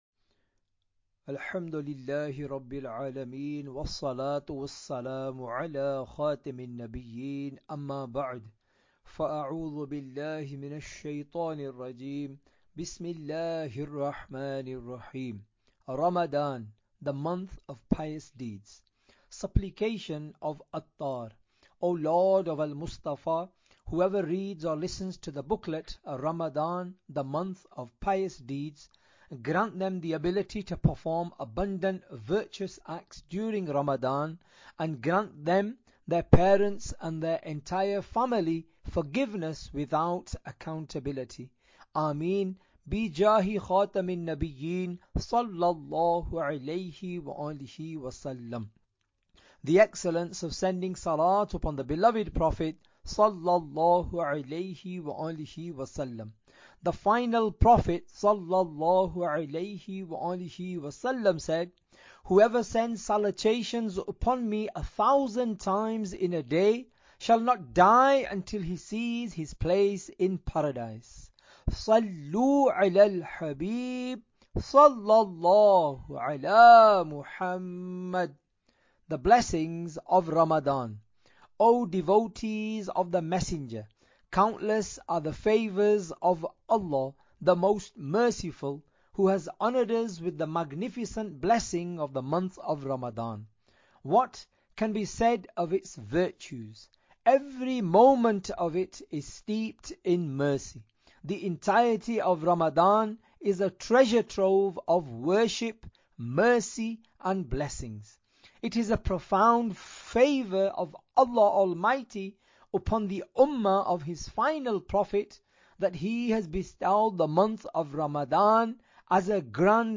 Audiobook - Ramadan The Month of Pious Deeds (English)